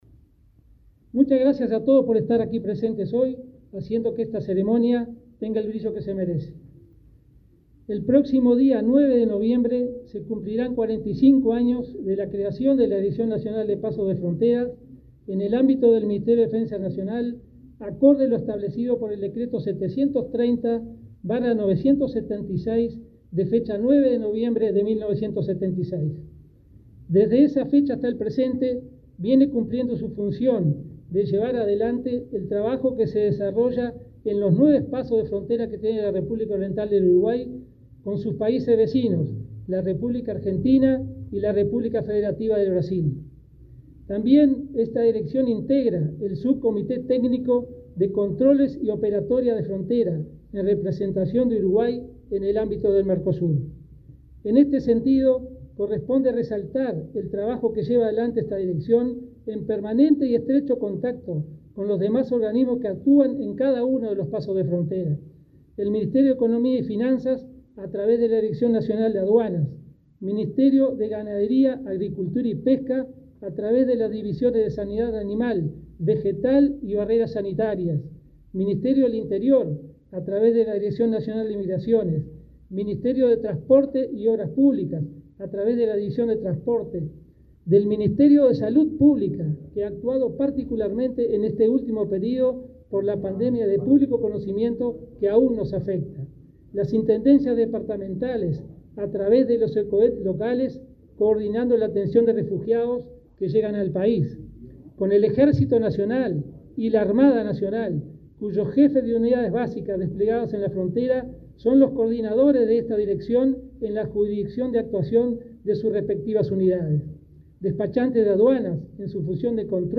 Palabras del director nacional de Pasos de Frontera, Milton Machado
Palabras del director nacional de Pasos de Frontera, Milton Machado 05/11/2021 Compartir Facebook X Copiar enlace WhatsApp LinkedIn El director nacional de Pasos de Frontera, Milton Machado, participó, este viernes 5 en Montevideo, de la celebración del 45.° aniversario de esa dependencia del Ministerio de Defensa.